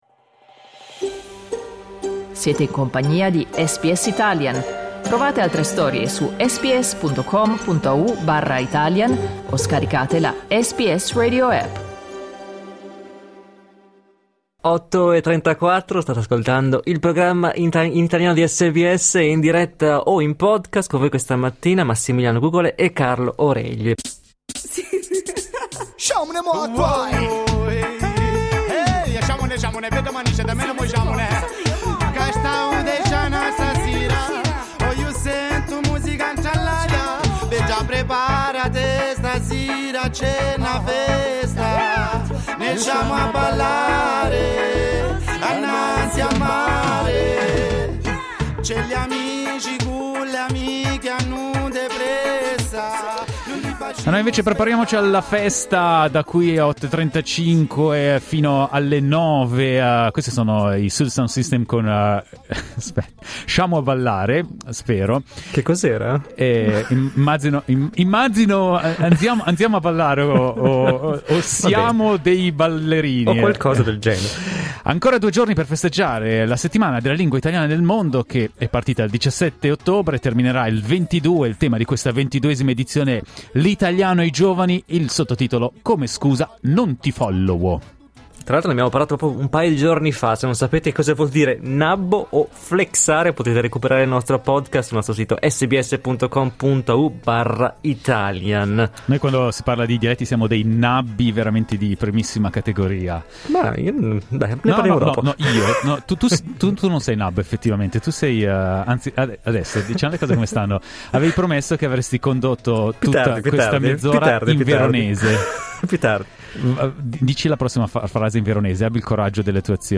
Abbiamo chiesto agli ascoltatori di SBS Italian di chiamarci e parlarci nel loro dialetto, con due ospiti speciali in studio